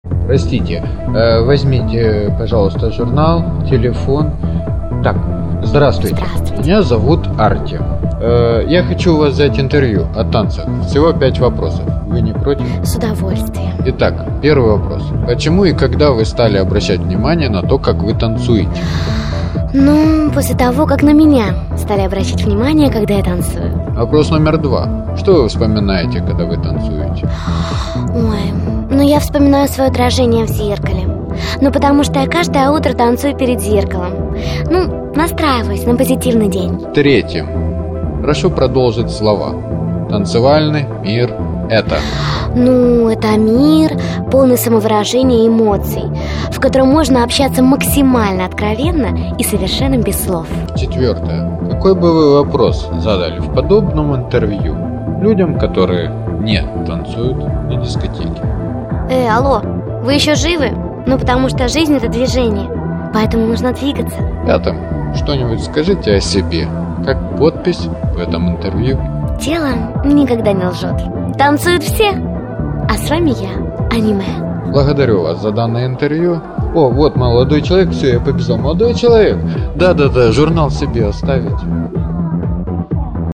И словил на дискотеке...
ИНТЕРВЬЮ (1,27 МБ)